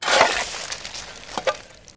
hand mining
wet2.wav